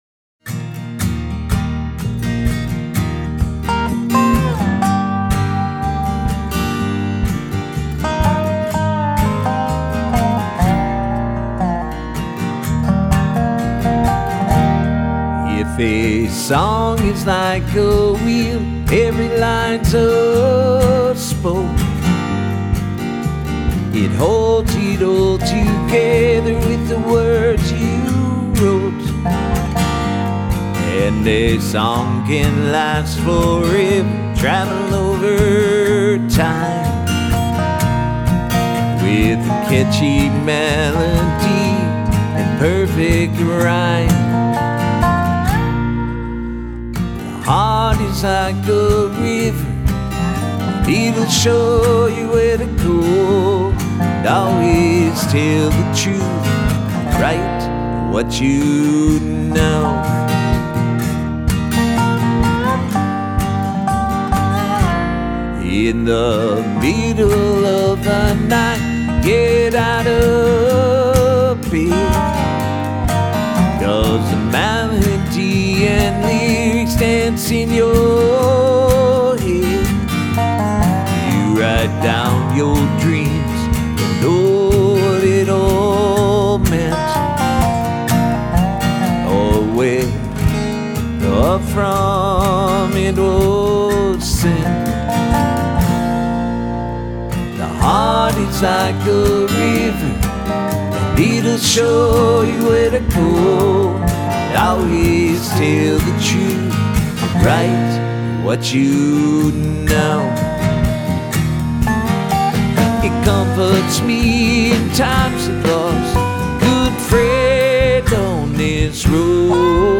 Minnesota Folk Singer and Songwriter